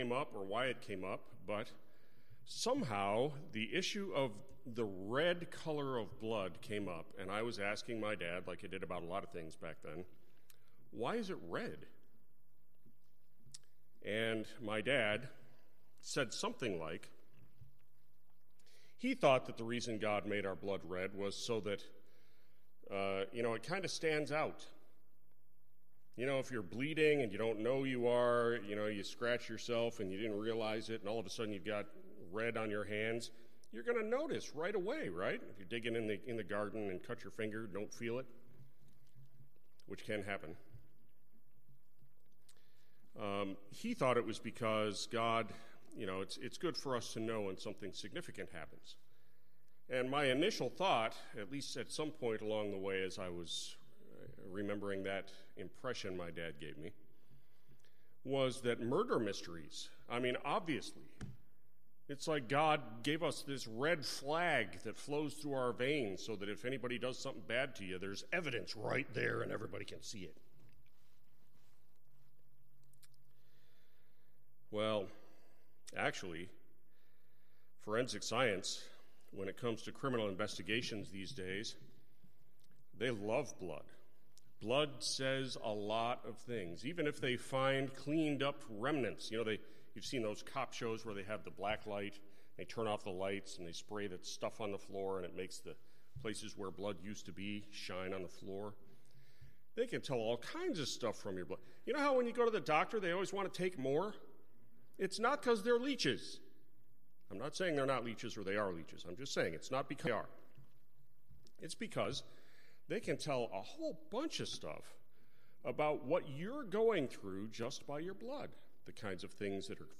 Sermons List